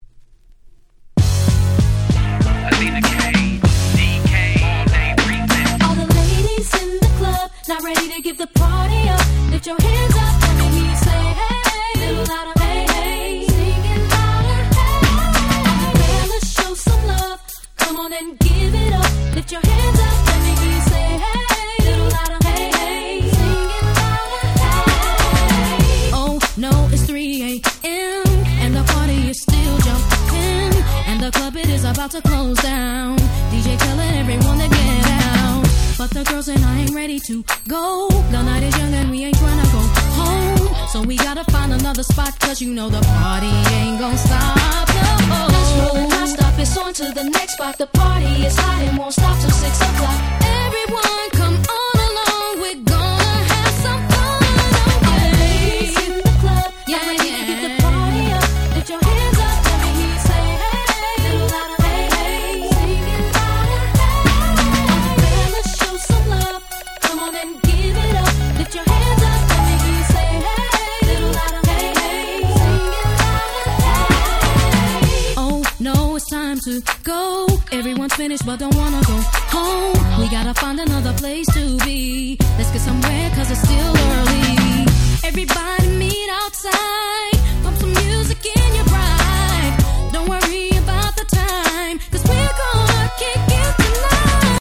01' Nice R&B !!
US Promo Only Remix !!